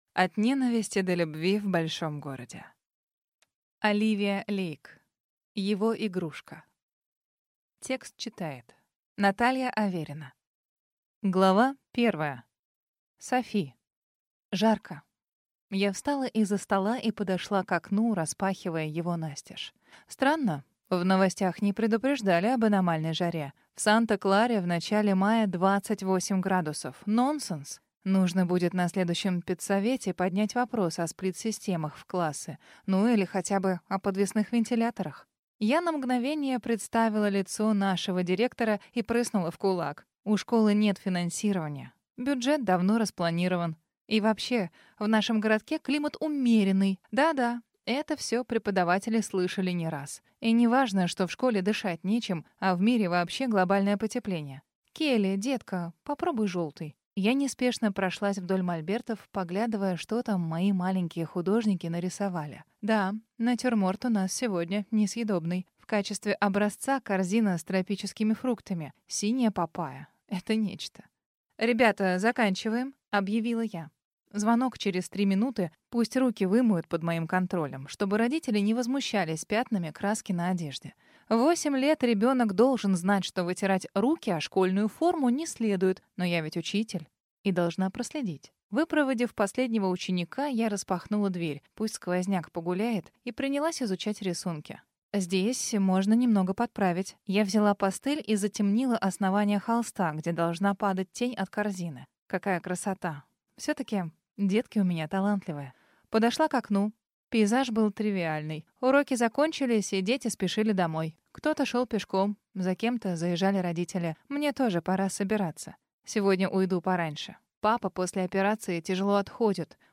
Aудиокнига Его игрушка